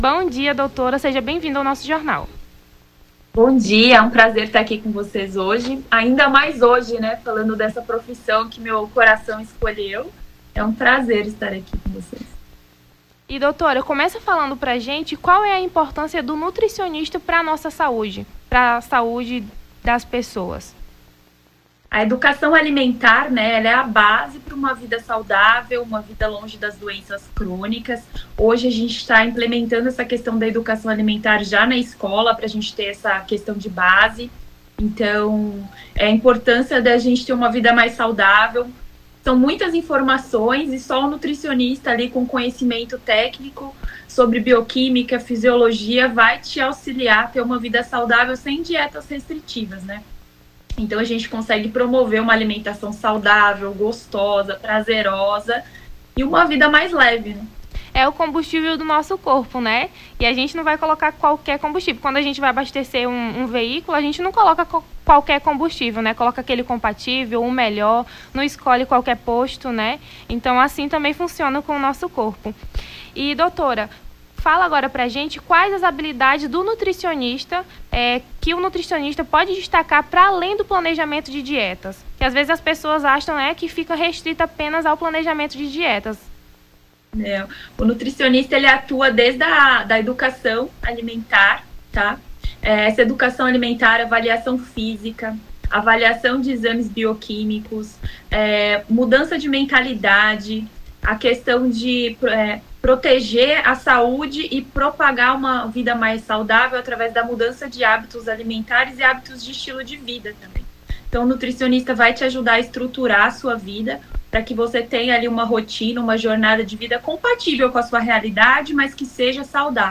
Nome do Artista - CENSURA - ENTREVISTA (DIA DO NUTRICIONISTA) 31-08-23.mp3